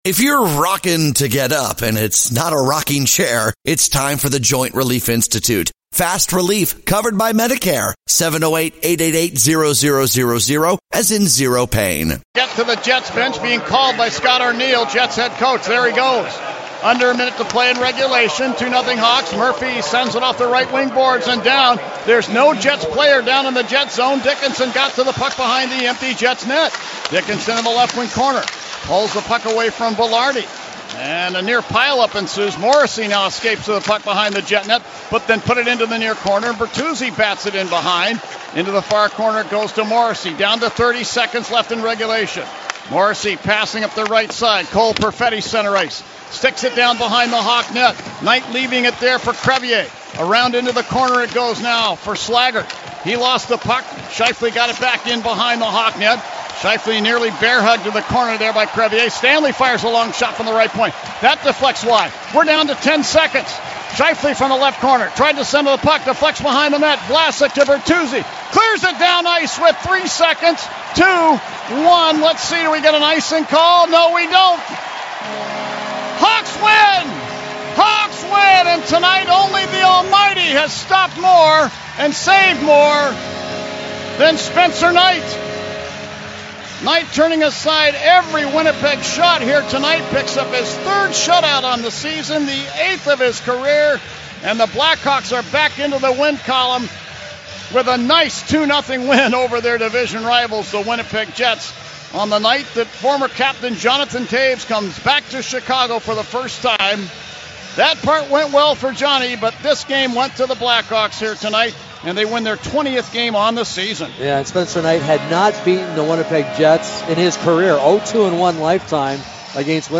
Later, we hear from Toews on his return and deafening reception. We also hear from Knight, Alex Vlasic, Oliver Moore, and head coach Jeff Blashill.